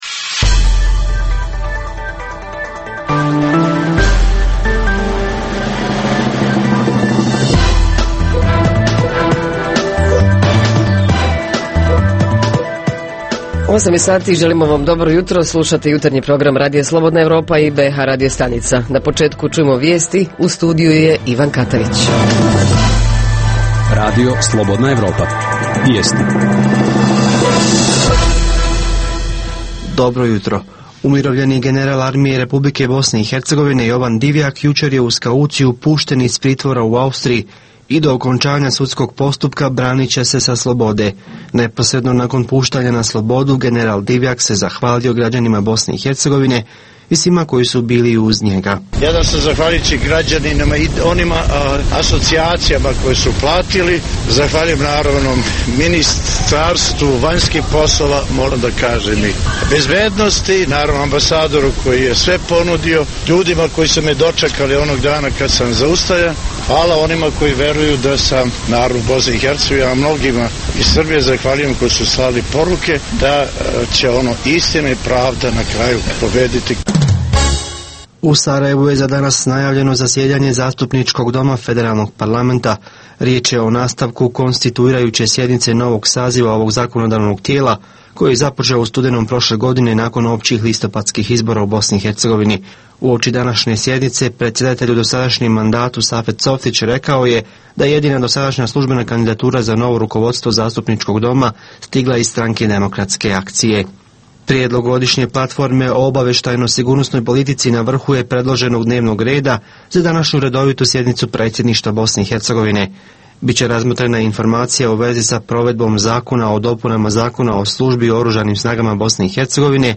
Kako sport i rekreaciju uvesti u svoj život barem jednom sedmično – ima li kakav recept za to? Reporteri iz cijele BiH javljaju o najaktuelnijim događajima u njihovim sredinama.